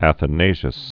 (ăthə-nāshəs), Saint.